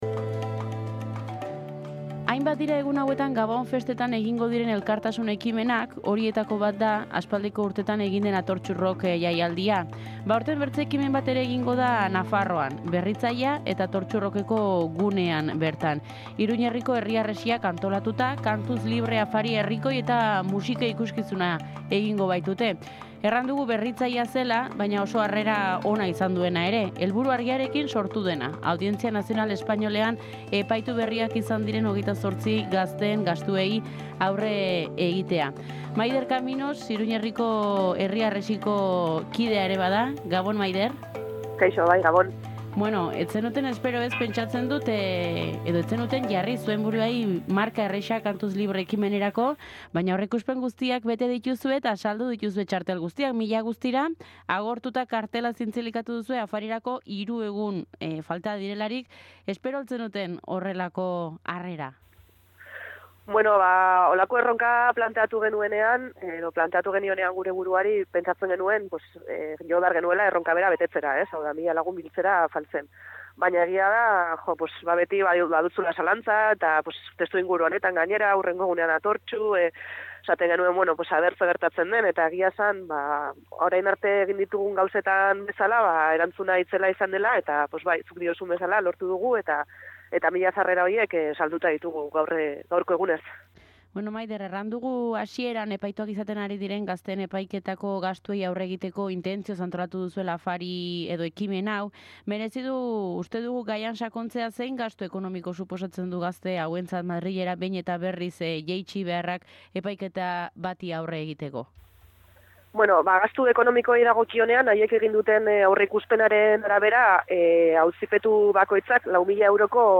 hitz egin dugu Gakoa saioan.